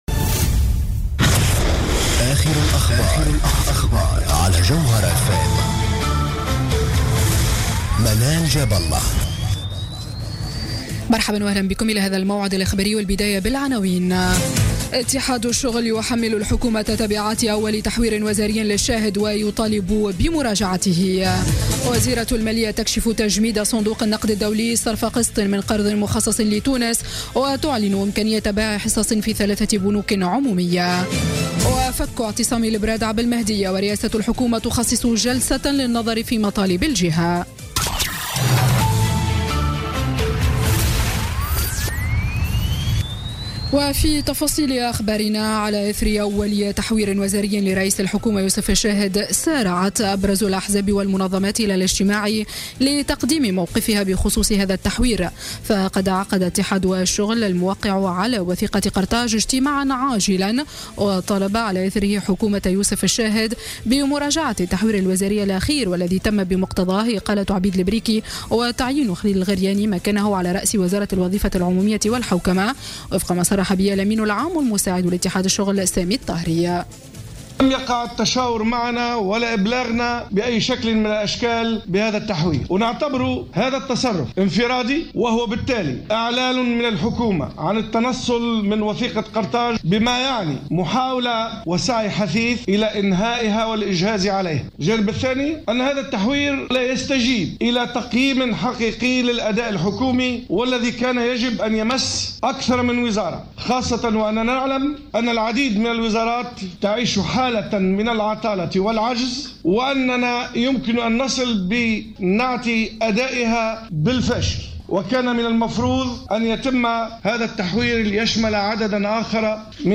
نشرة أخبار السابعة مساء ليوم الأحد 26 فيفري 2017